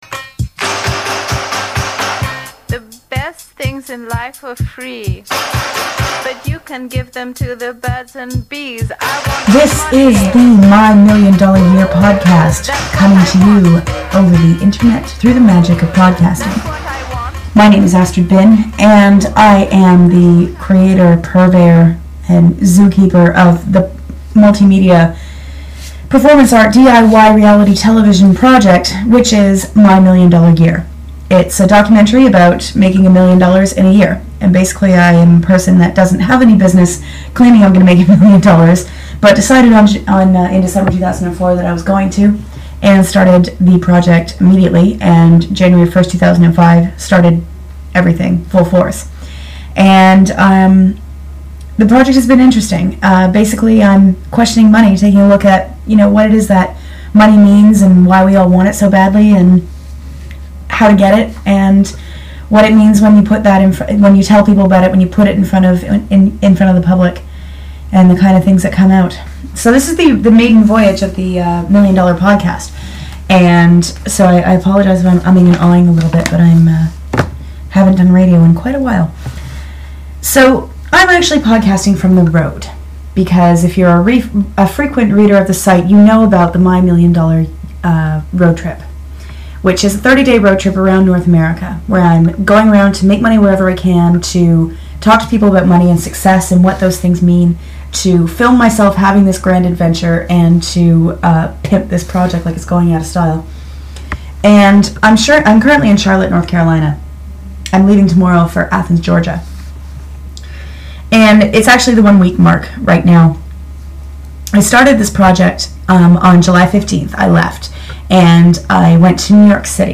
The first Podcast was made on My Million Dollar Roadtrip while on a stop in Charlotte, North Carolina.
July 17, 2005 - My Million Dollar Podcast from Charlotte, North Carolina!